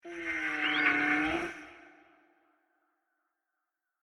Archaeopteryx Call 2
SFX
yt_sejWHqDYneI_archaeopteryx_call_2.mp3